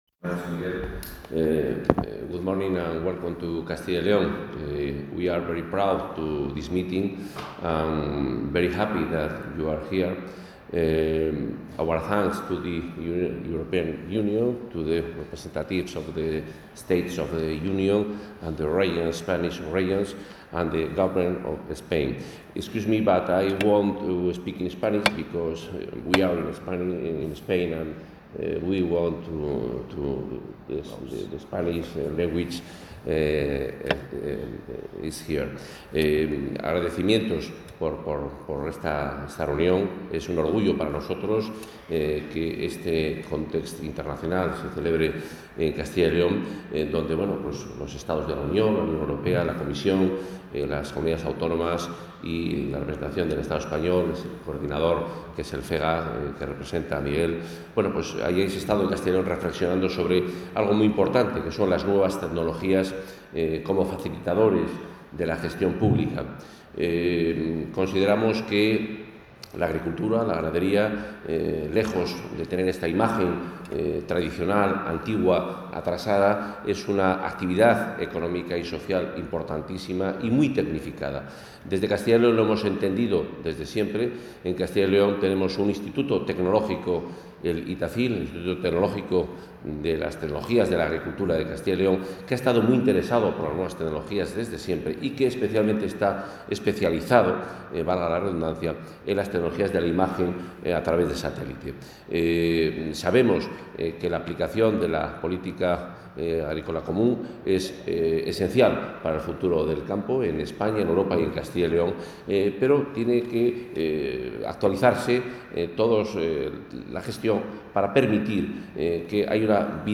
Intervención del consejero de Fomento y Medio Ambiente en el 'Seminario sobre el uso de las nuevas tecnologías para los controles de la PAC'